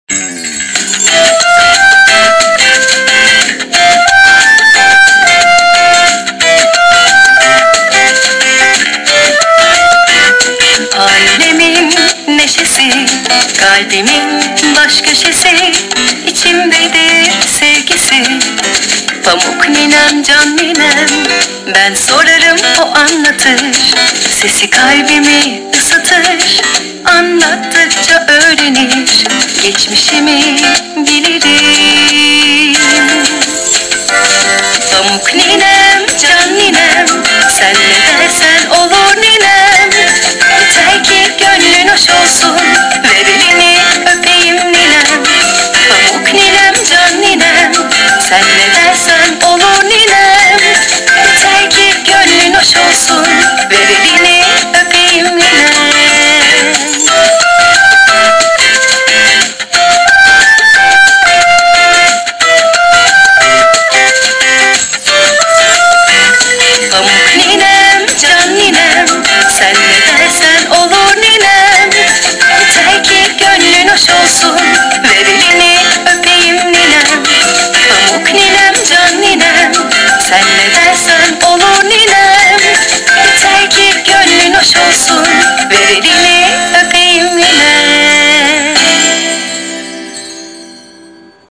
2.-Sinif-Turkce-Ninem-Sarkisi.mp3